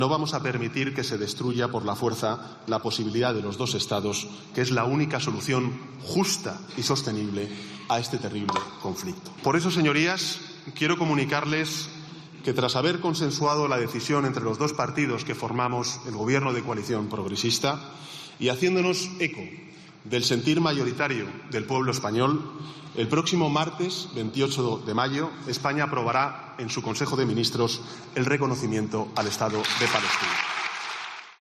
Sánchez anuncia en el Congreso que España reconocerá el estado palestino el 28 de mayo
"Quiero comunicarles que el próximo martes 28 de mayo España aprobará el reconocimiento al estado de Palestina", así lo ha adelantado el Presidente del Gobierno en el Pleno del Congreso de los Diputados.